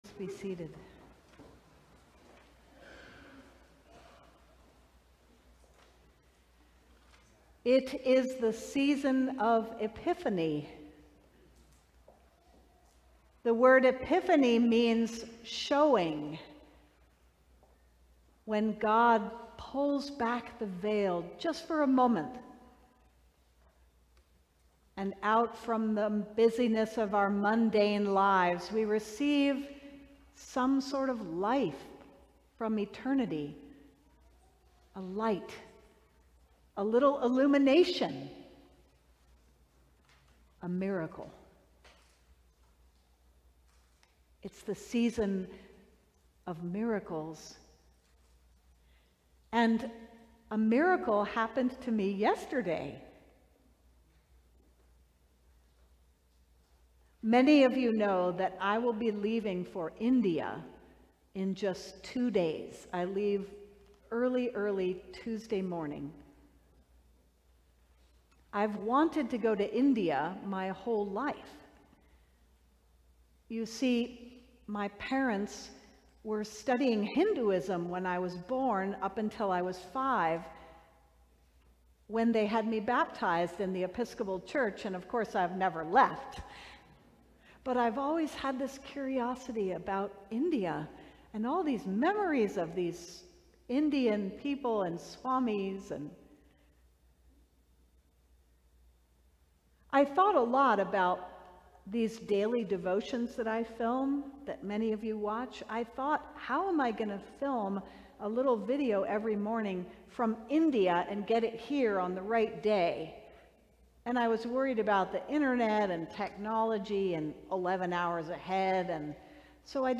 Sermon: The Book Miracle - St. John's Cathedral
Third Sunday after Epiphany